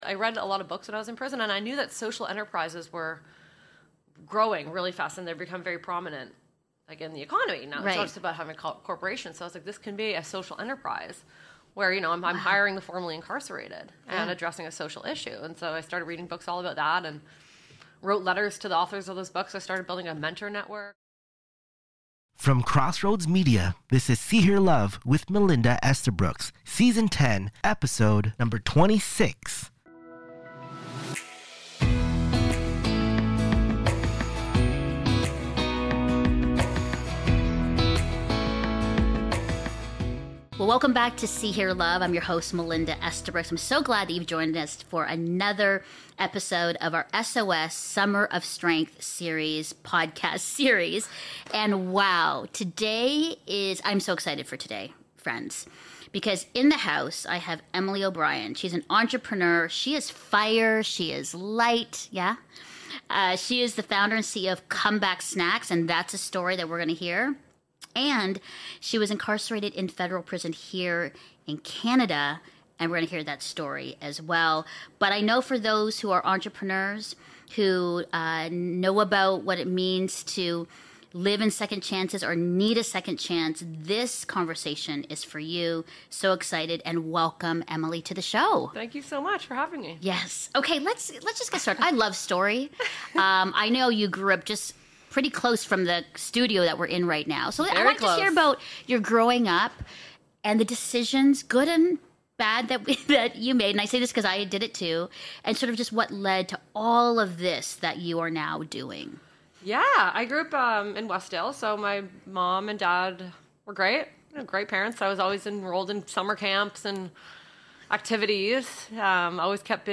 Season 10 SOS Podcast Episode 4: One on One Interview